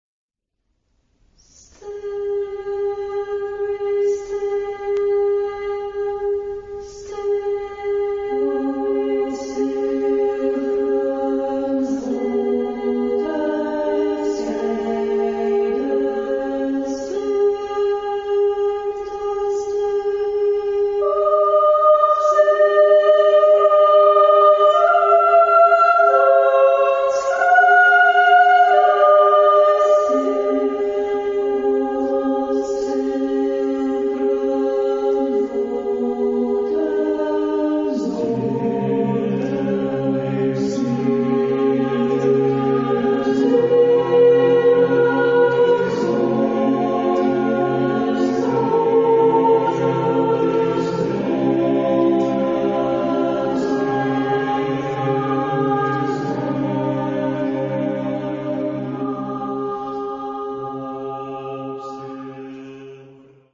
Genre-Style-Form: Choir ; Secular ; Contemporary
Mood of the piece: poetic
Type of Choir: SATB  (4 mixed voices )
Tonality: C major